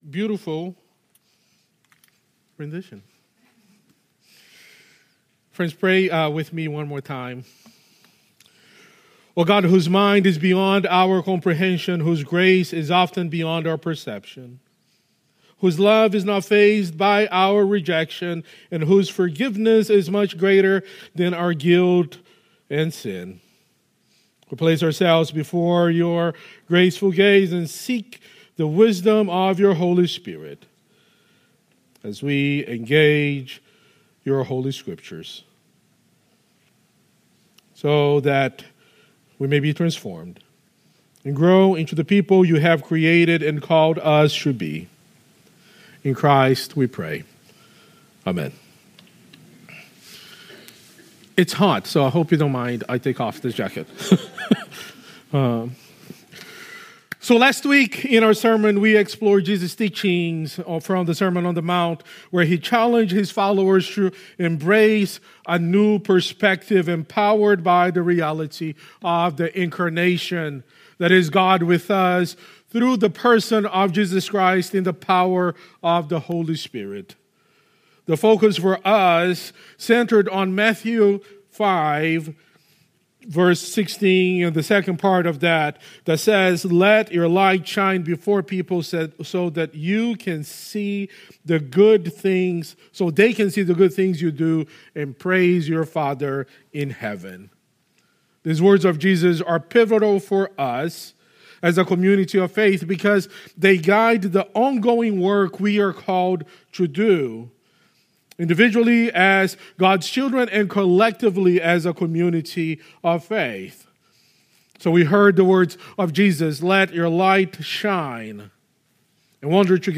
Sermons | Hilldale United Methodist Church